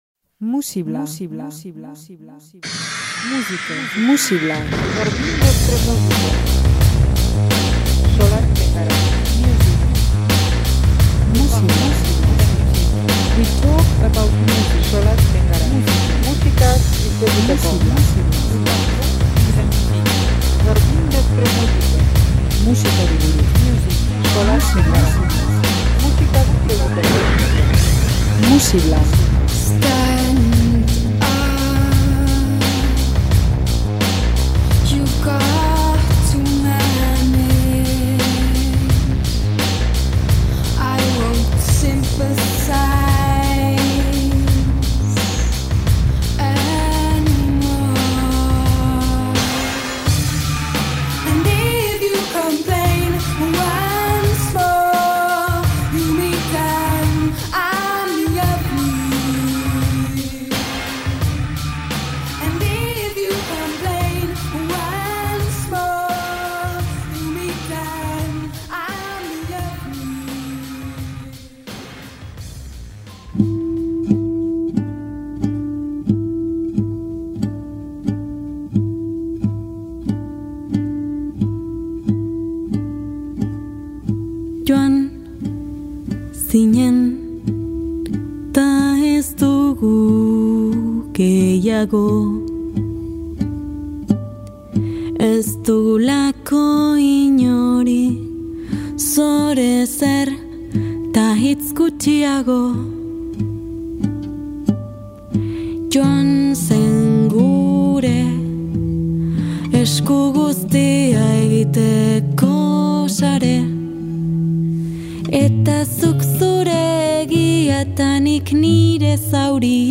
Delikatua baina indartsua, lotsagabe eta freskoa.